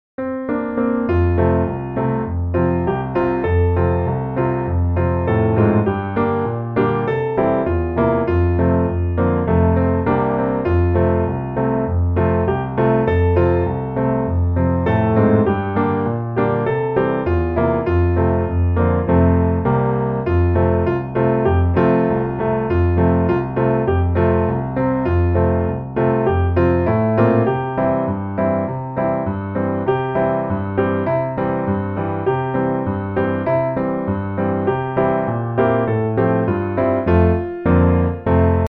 F大調